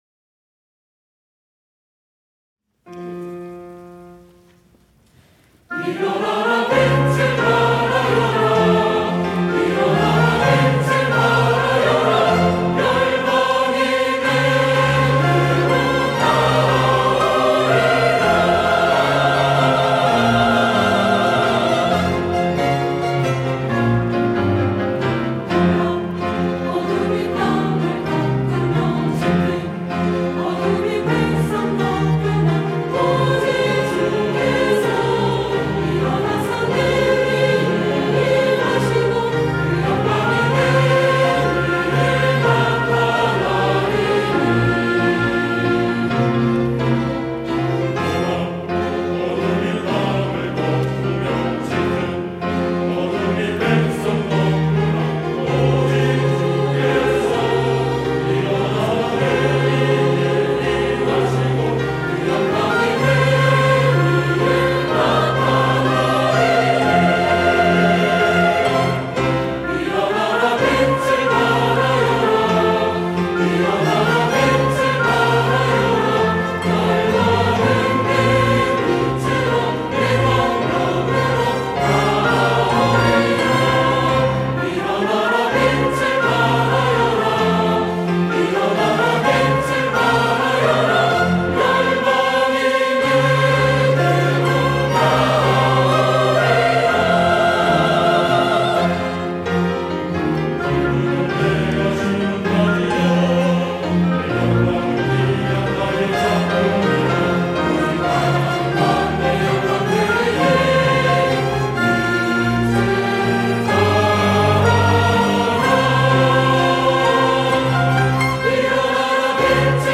호산나(주일3부) - 일어나 빛을 발하여라
찬양대